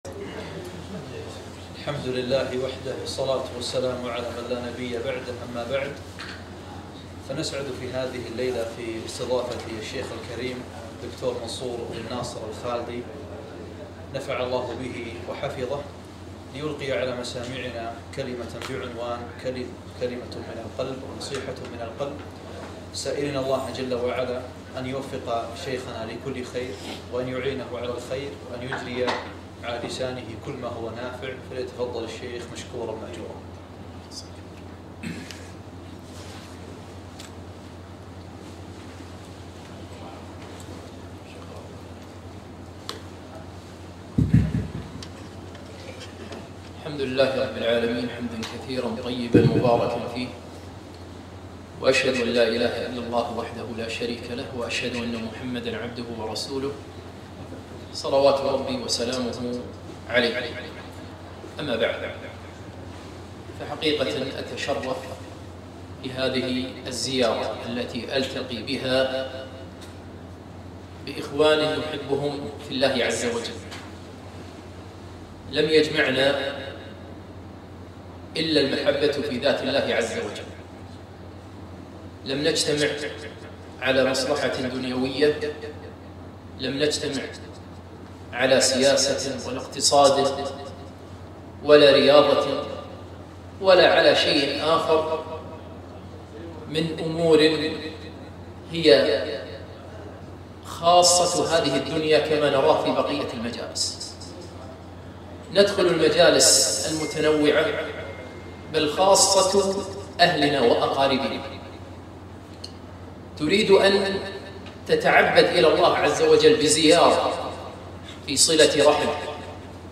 محاضرة قيمة - نصيحة من القلب